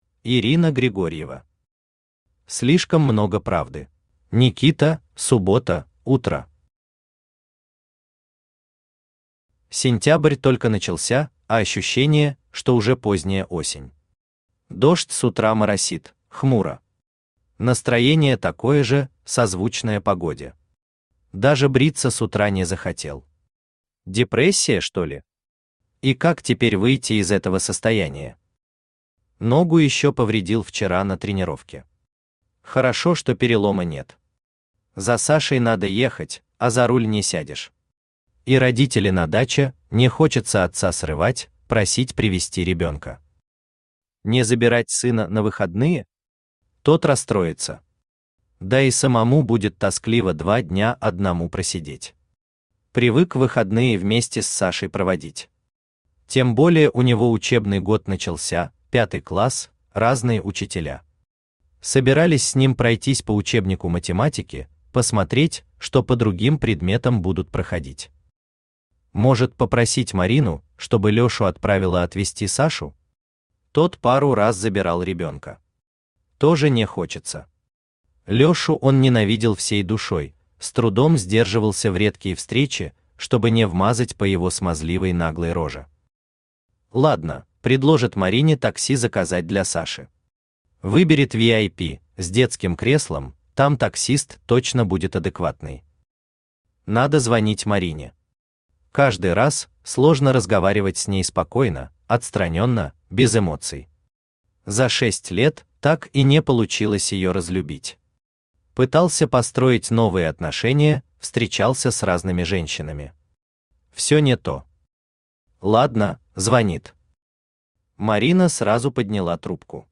Аудиокнига Слишком много правды | Библиотека аудиокниг
Aудиокнига Слишком много правды Автор Ирина Григорьева Читает аудиокнигу Авточтец ЛитРес.